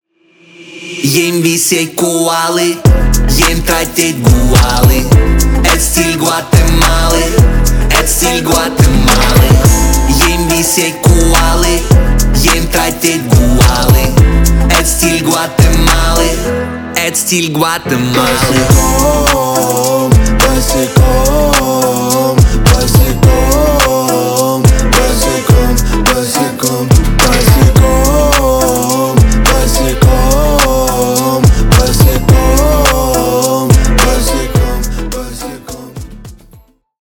• Качество: 320 kbps, Stereo
Поп Музыка
весёлые